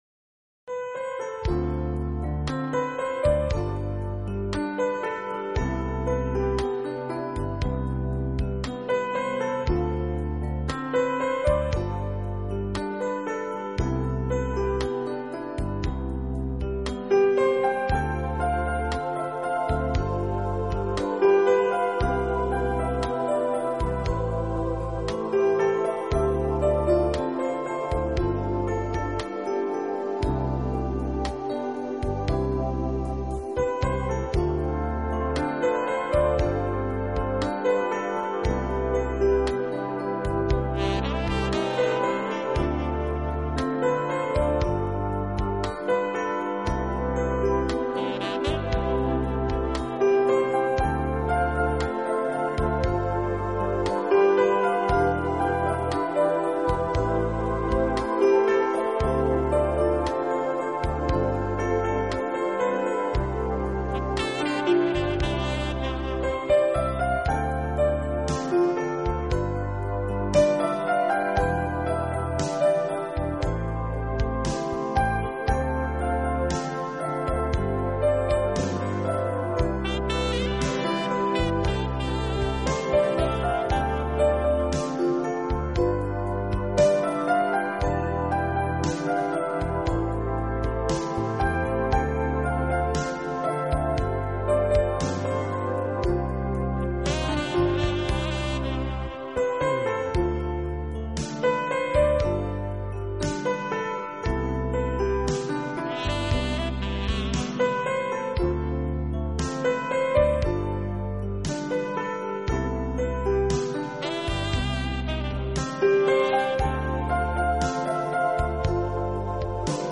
音乐风格：New Age